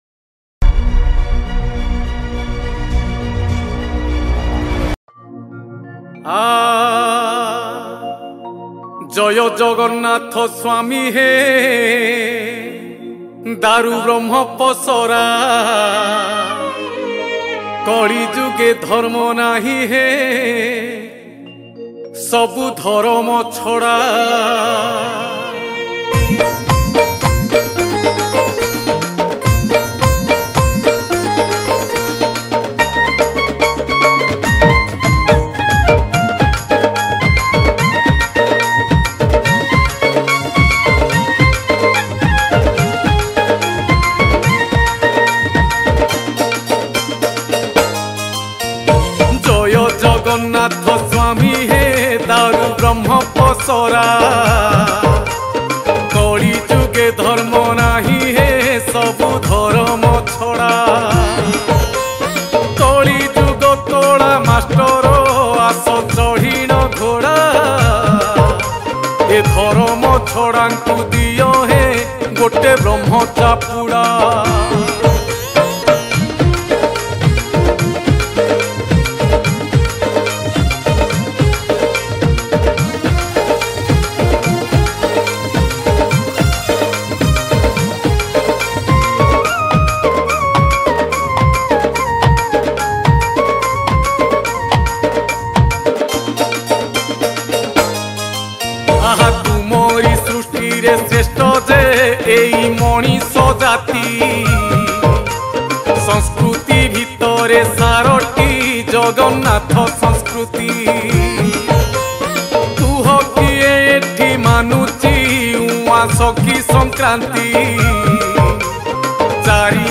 Keyboard